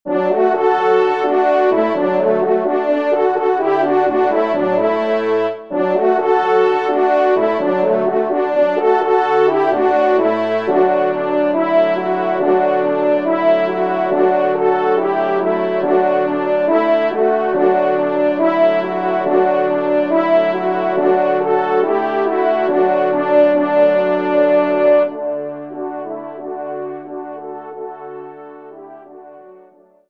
Genre :  Musique Religieuse pour Trompes ou Cors en Ré
ENSEMBLE